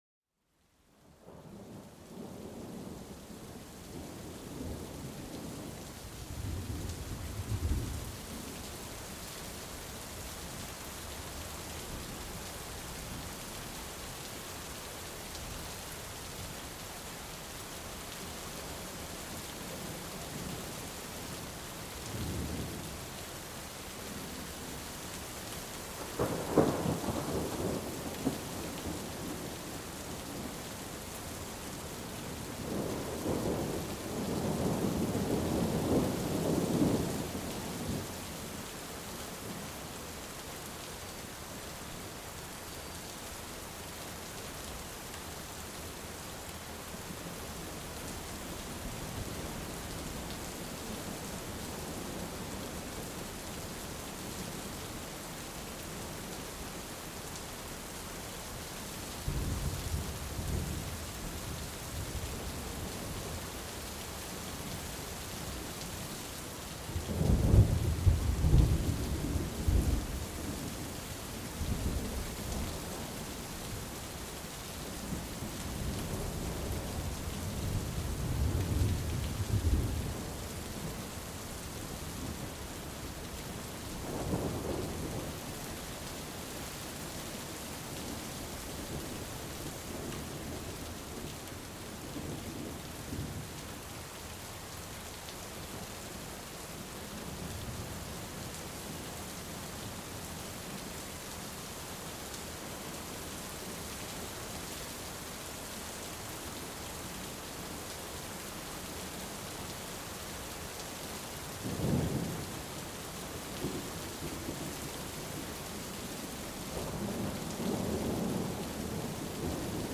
Tonnerre et pluie : Relaxation et immersion apaisante
Plongez dans l'ambiance apaisante d'une pluie intense accompagnée de coups de tonnerre lointains. Chaque goutte et chaque roulement sonore évoque une atmosphère de confort et de sécurité, idéale pour se détendre.